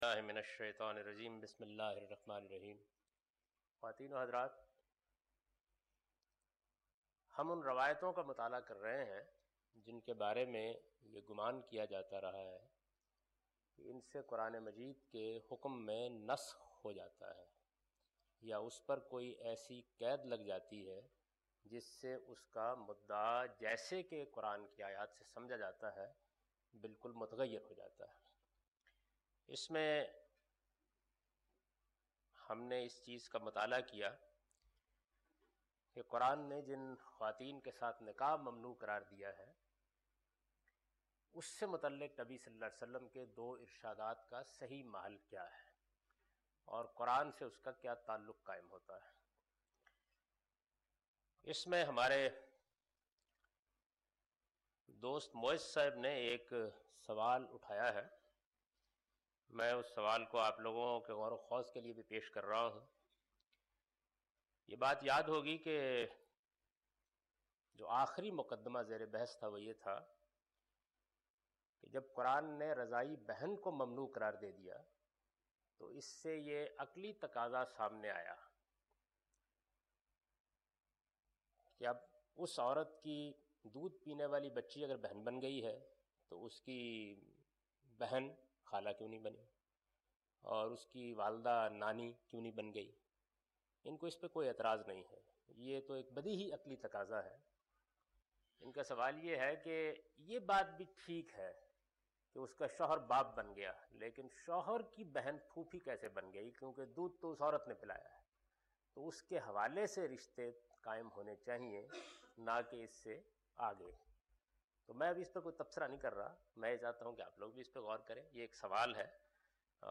A comprehensive course on Islam, wherein Javed Ahmad Ghamidi teaches his book ‘Meezan’.
In this lecture he teaches the the ruling of Hadith in order to interpret and understand the Quran. (Lecture no.34– Recorded on 17th May 2002)